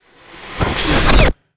magic_conjure_charge1_03.wav